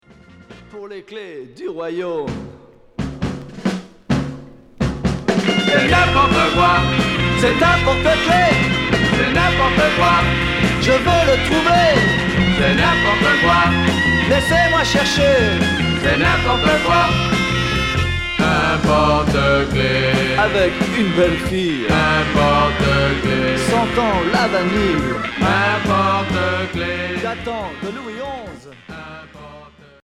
Garage beat